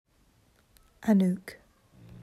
Rhymes with Luke, stress on second syllable, first syllable more AH than UH.